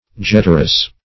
Search Result for " jeterus" : The Collaborative International Dictionary of English v.0.48: Jeterus \Jet"e*rus\, n. (Bot.)